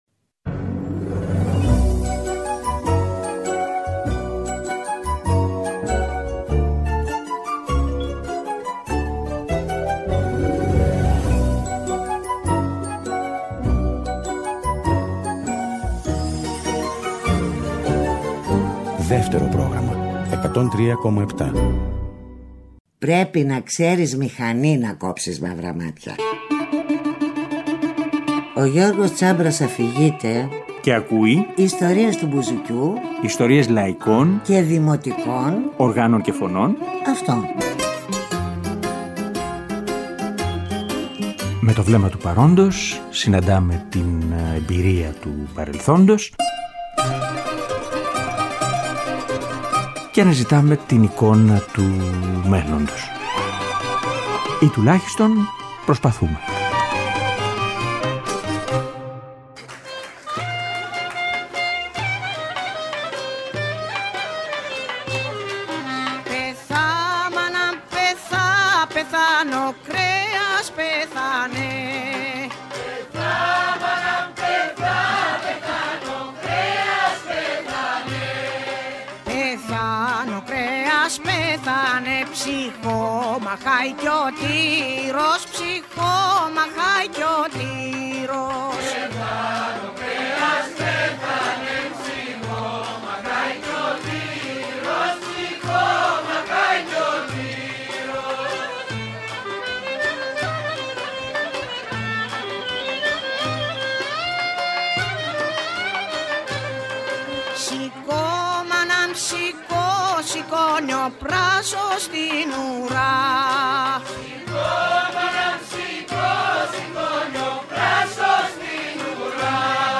Η εκπομπή και το γλέντι της Αποκριάς ολοκληρώνονται με μια σειρά πασίγνωστα δημοτικά τραγούδια απ’ όλη την Ελλάδα με το κλαρίνο